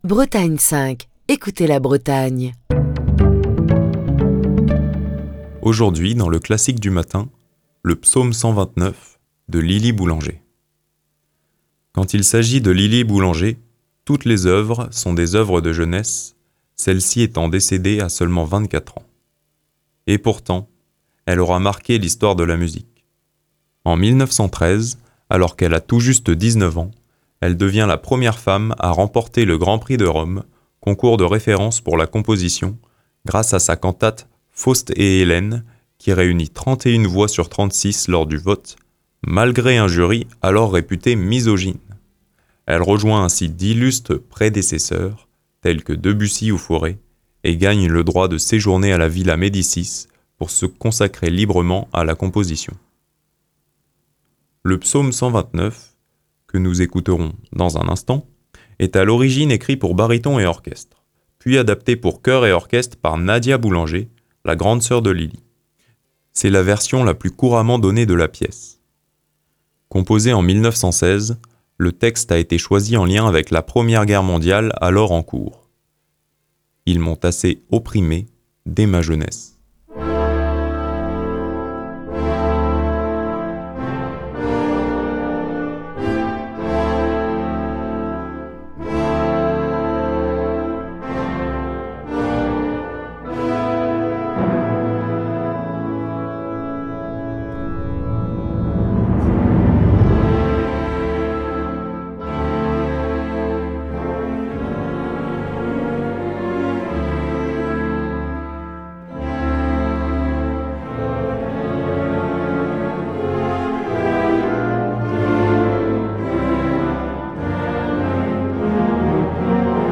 Sa musique, presque toujours sombre et grave, est caractérisée par une grande sensibilité et un langage harmonique aventurier.